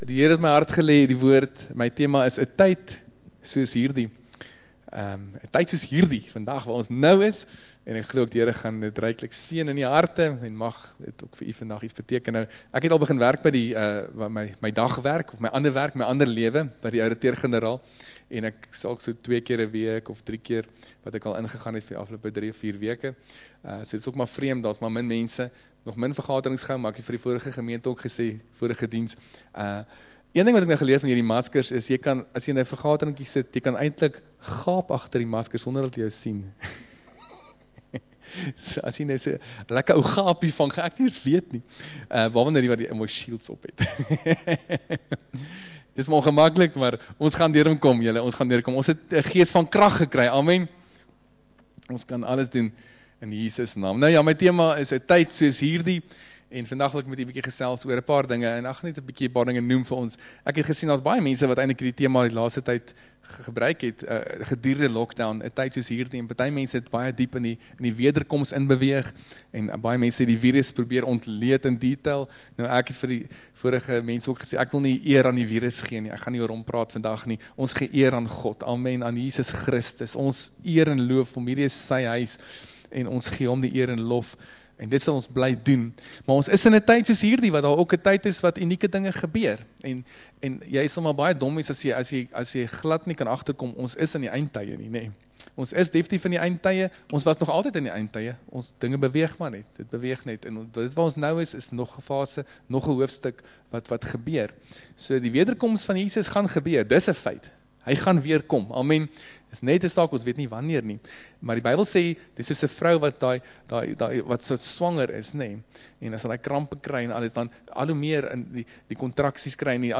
Laai Af: Preek